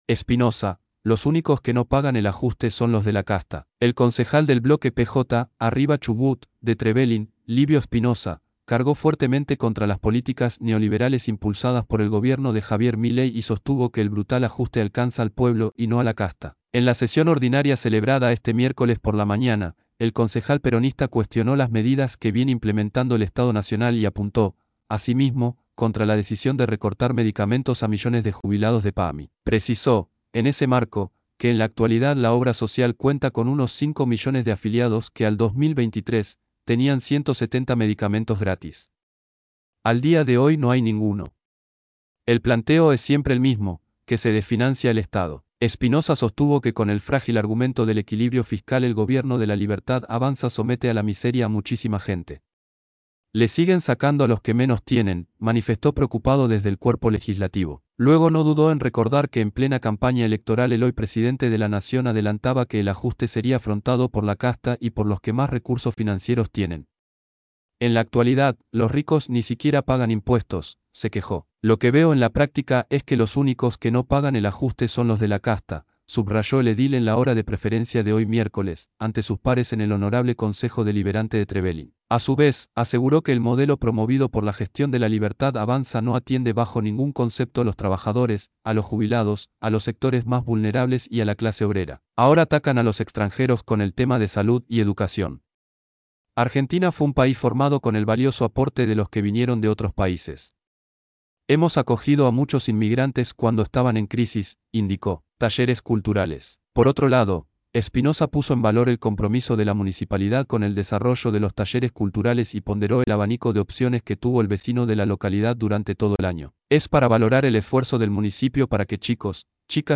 En la sesión ordinaria celebrada este miércoles por la mañana, el concejal peronista cuestionó las medidas que viene implementando el Estado nacional y apuntó, asimismo, contra la decisión de recortar medicamentos a millones de jubilados de PAMI.
livio_espinoza_-_xvi_sesion_ordinaria_2024.mp3